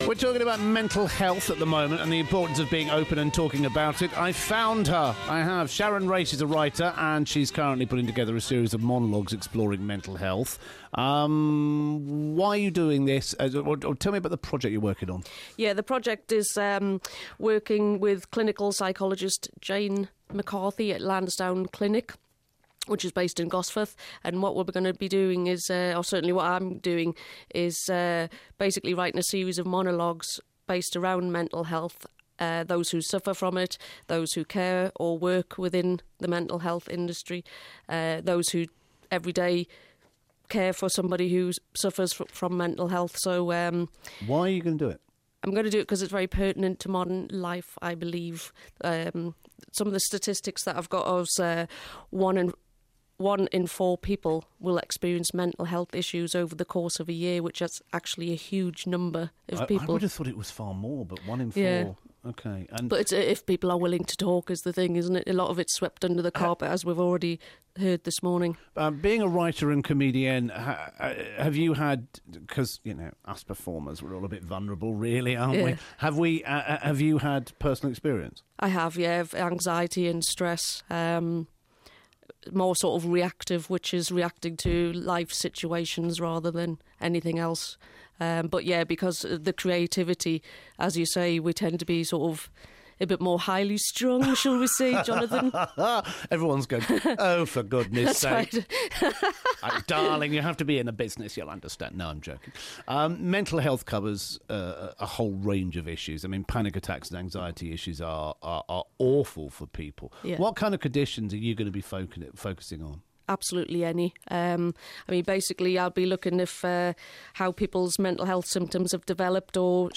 The mid-morning show